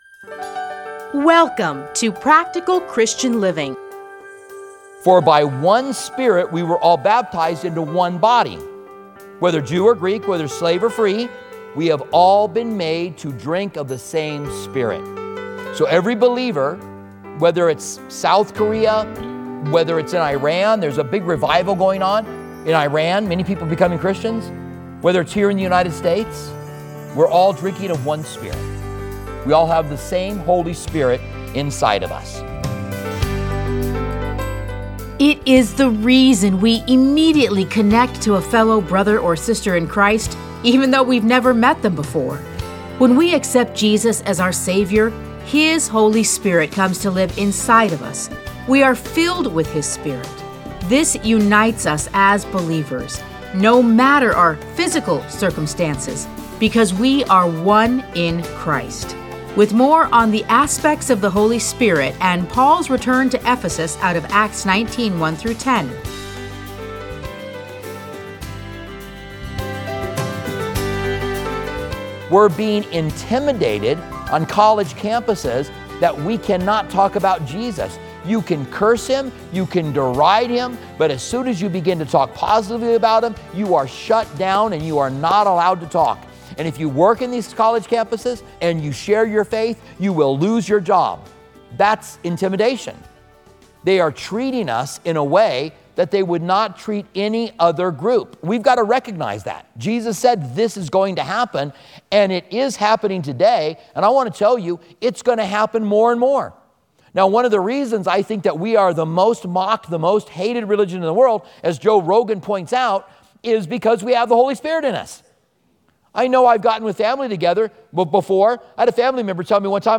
Listen to a teaching from Acts 19:1-10.